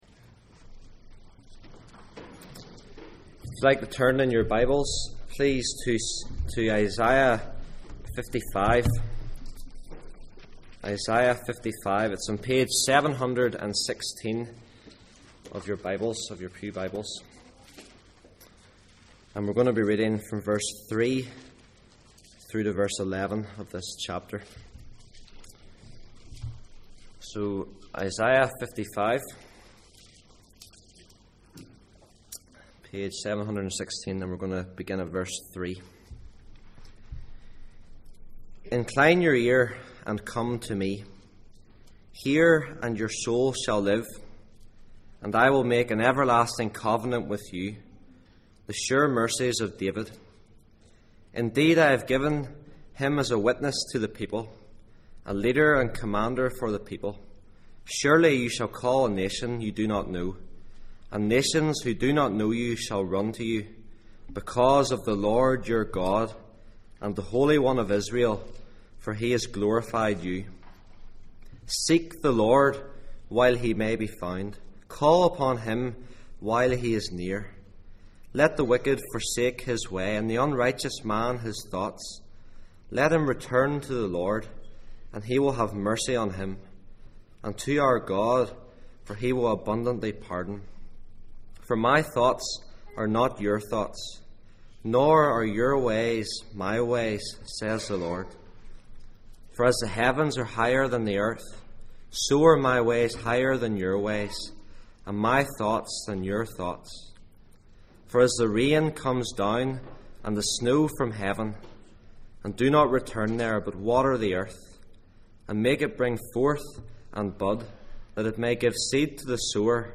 Passage: Isaiah 55:3-11, Ephesians 2:1-10 Service Type: Sunday Morning %todo_render% « The King has the last word Be careful